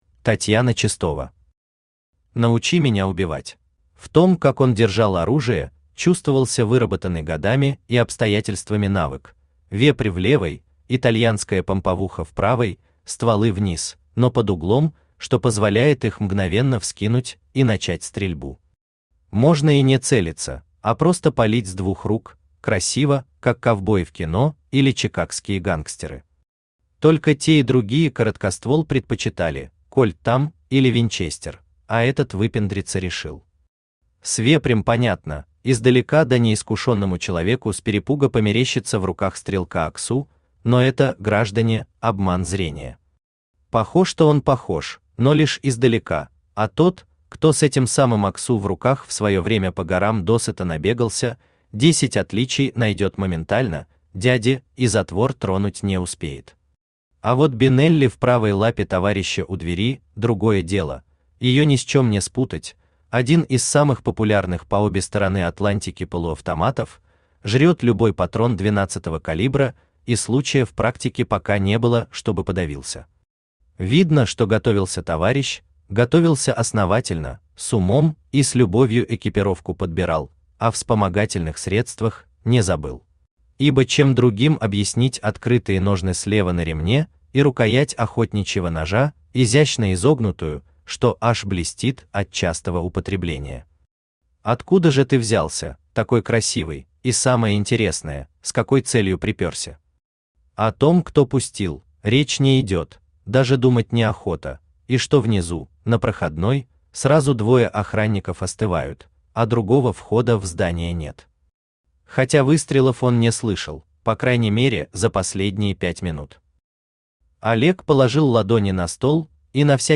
Aудиокнига Научи меня убивать Автор Татьяна Чистова Читает аудиокнигу Авточтец ЛитРес.